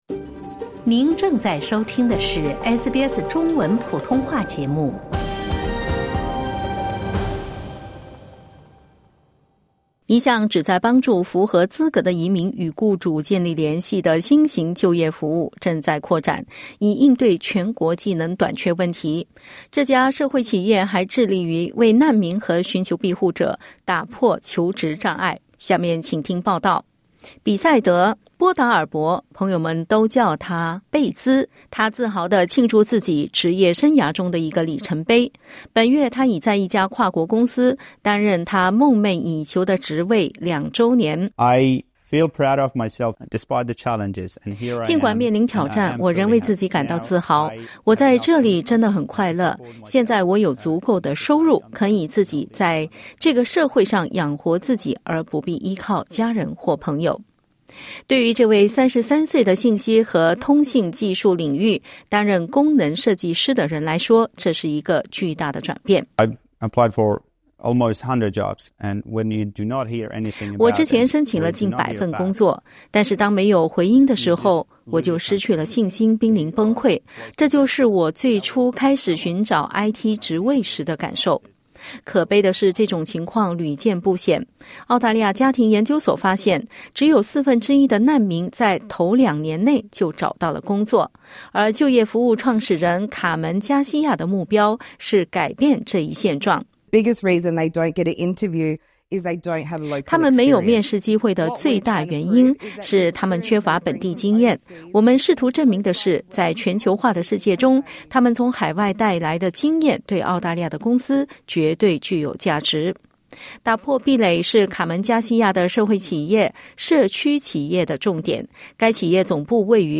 （点击音频收听详细报道） 一项旨在帮助符合资格的移民与雇主建立联系的新型就业服务正在扩展，以应对全国技能短缺问题。